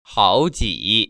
[hăojĭ] 하오지